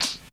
Closed Hats
Medicated Hat 16.wav